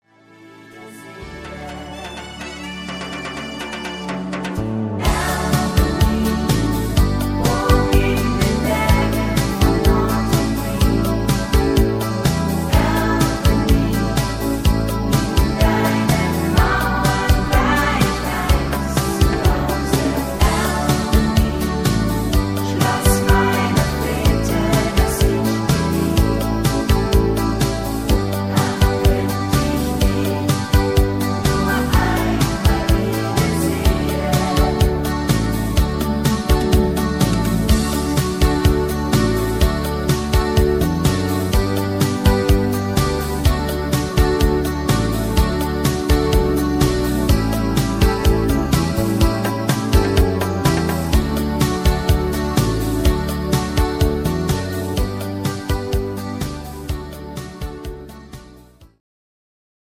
MP3 Playbacks2
Rhythmus  Light Beat
Art  Deutsch, Schlager 80er